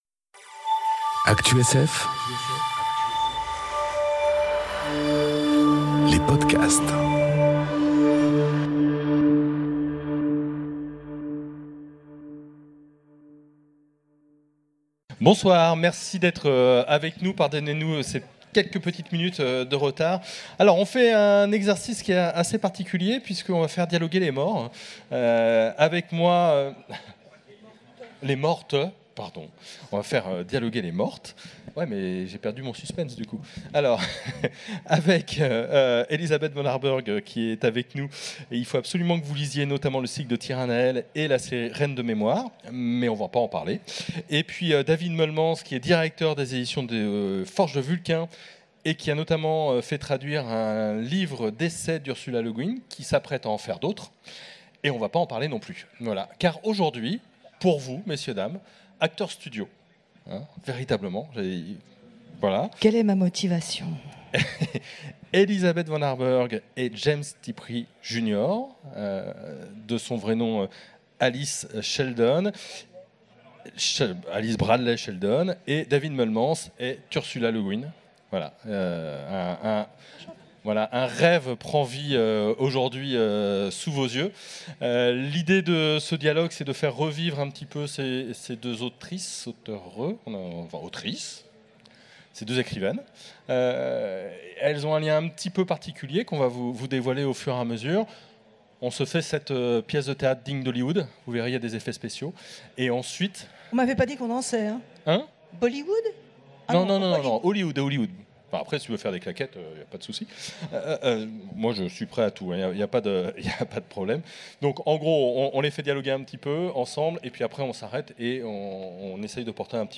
Conférence Dialogue entre les morts : Tiptree et Ursula K. Le Guin enregistrée aux Utopiales 2018